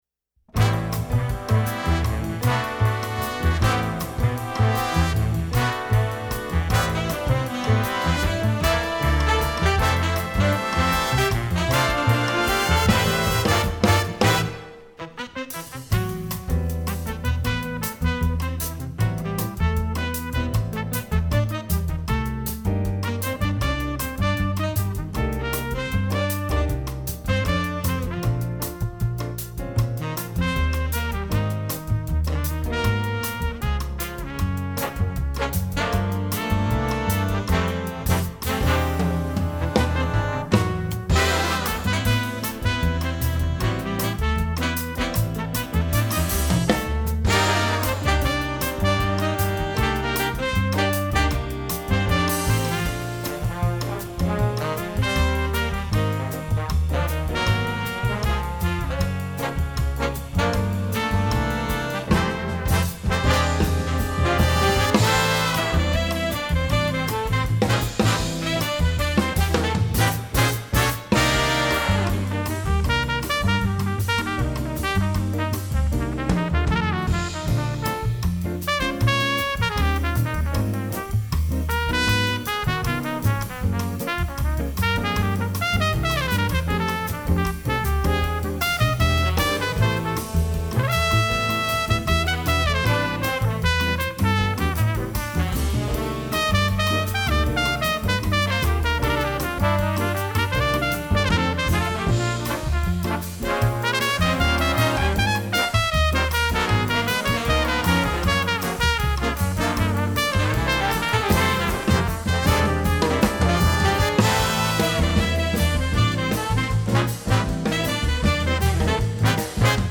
Gattung: für Jazz Ensemble
Besetzung: Jazz-Ensemble